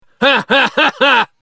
Смех Стэна